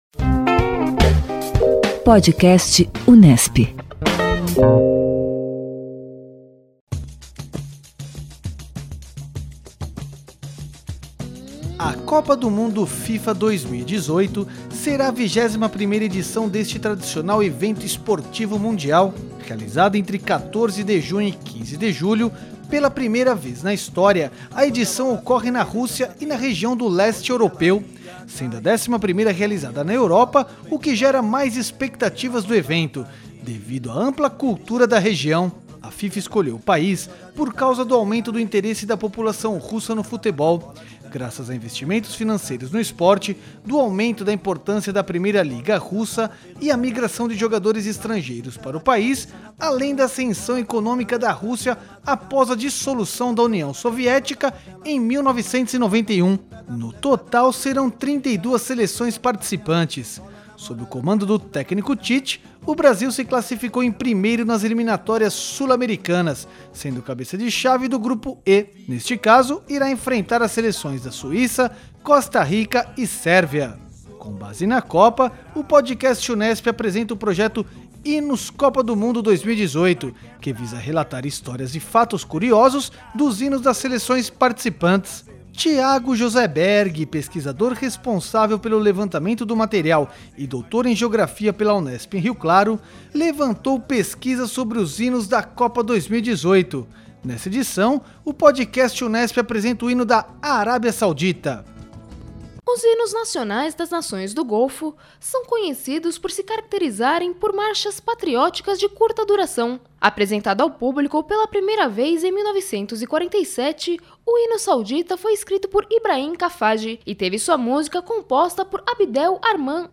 Nesta edição, o Podcast Unesp apresenta o hino da Árabia Saudita. Este Podcast conta com a canção "O Jogador" (Edvaldo Santana e Lenine) e com o Hino da Árabia Saudita.